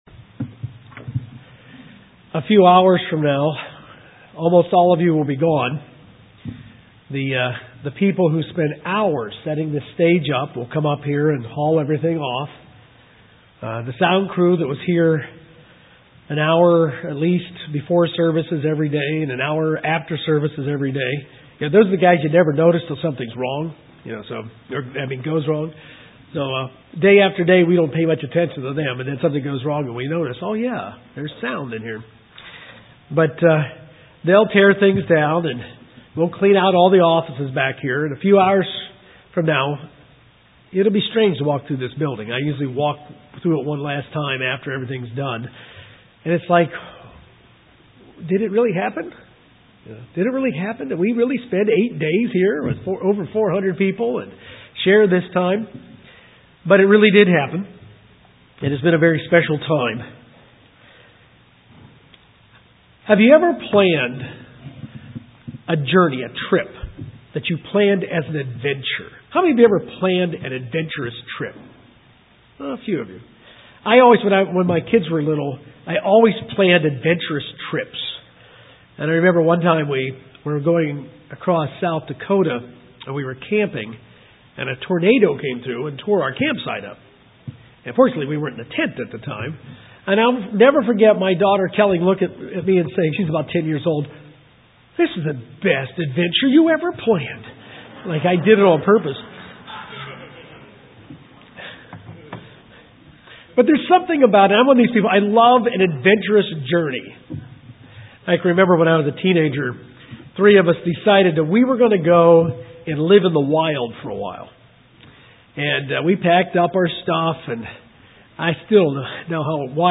Final Sermon Eighth Day of FOT New Braunfels.